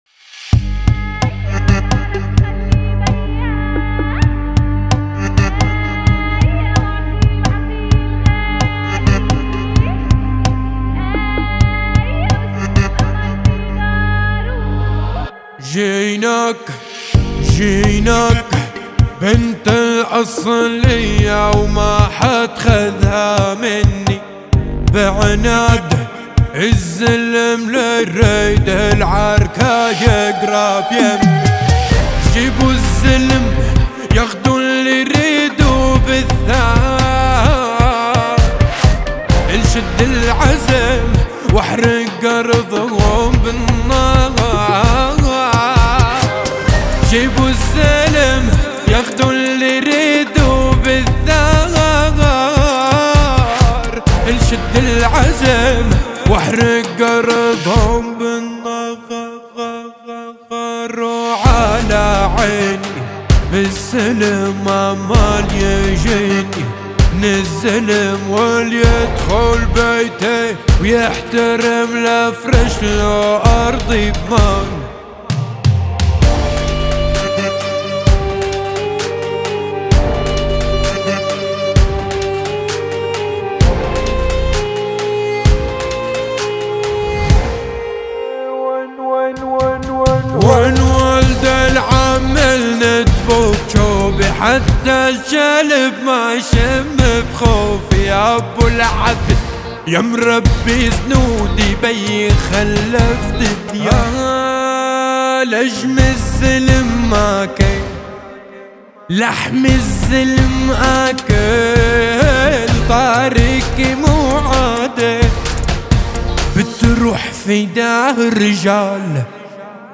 مرحة تناسب أجواء الاحتفال بالعيد.